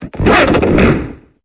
Cri d'Ouvrifier dans Pokémon Épée et Bouclier.